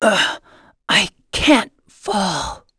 Theo-Vox_Dead.wav